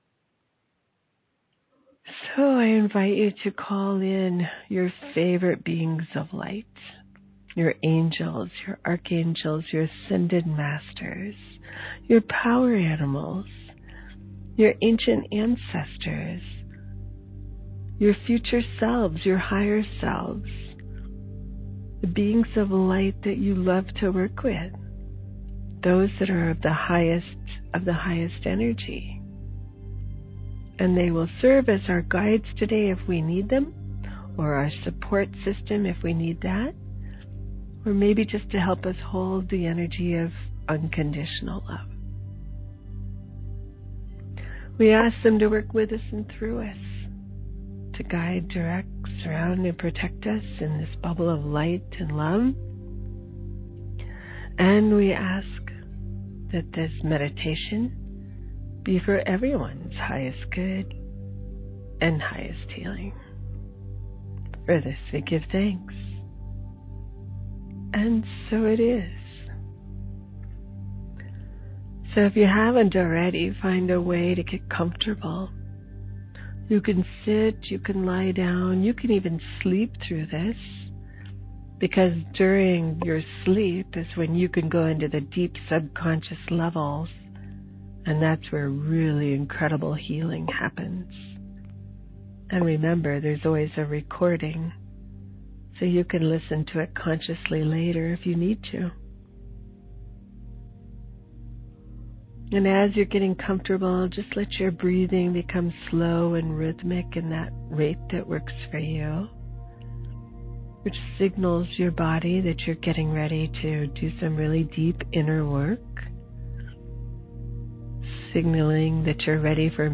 I would love to share this guided meditation with you.